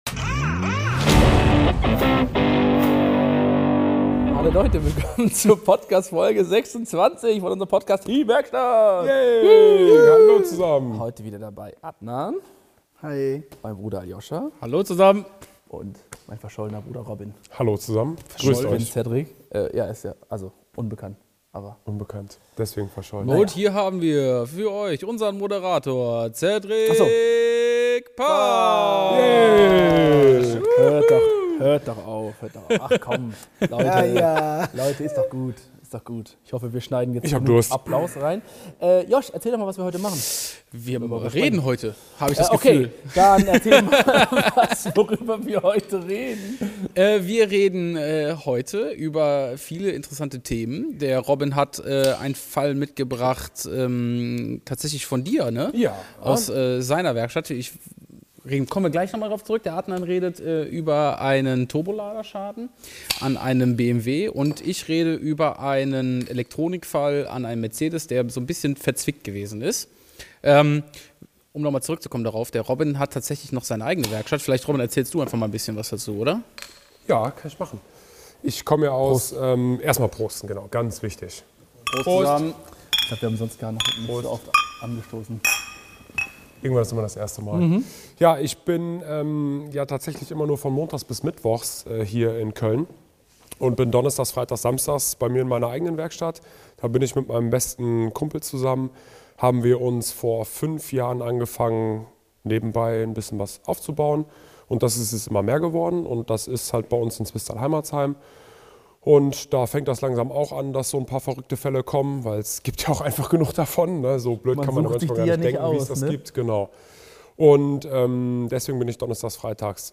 TEAM WERKSTATT | Der Feierabend-Talk aus der Werkstatt der Autodoktoren #26 | SO schei** finden wir E-AUTOS wirklich!